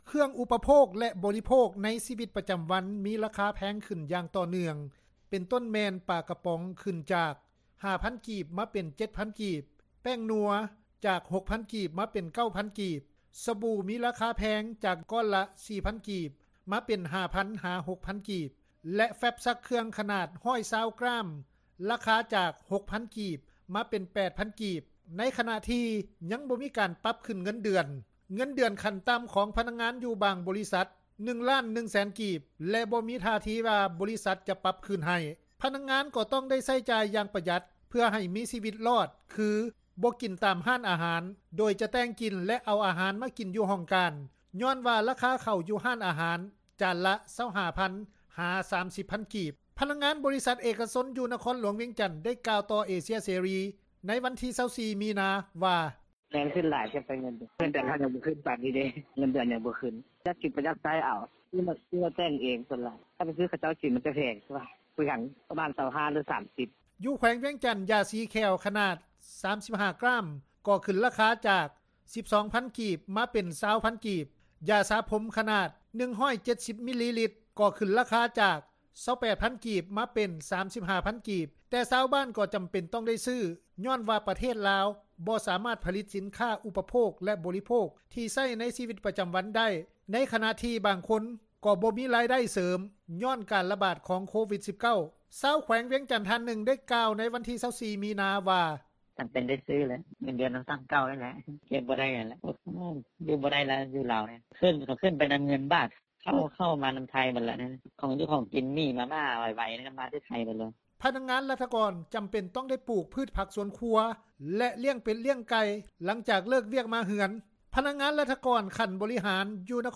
ຊາວແຂວງວຽງຈັນ ທ່ານນຶ່ງ ໄດ້ກ່າວວ່າ ໃນວັນທີ 24 ມີນາ ວ່າ:
ແມ່ຄ້າ ຢູ່ ແຂວງວຽງຈັນ ຄົນນຶ່ງ ໄດ້ກ່າວວ່າ: